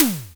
Snaredrum-02.wav